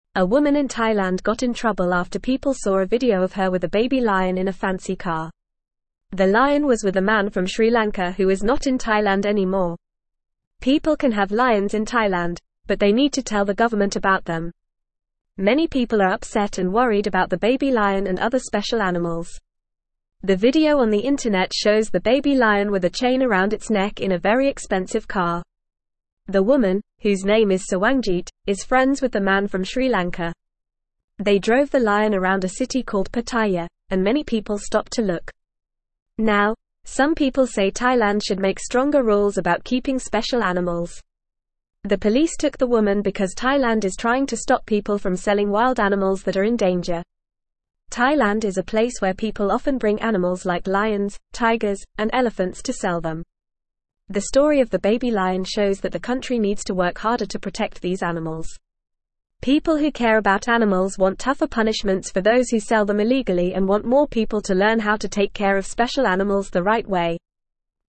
Fast
English-Newsroom-Lower-Intermediate-FAST-Reading-Trouble-for-Woman-Who-Let-Baby-Lion-Ride-in-Car.mp3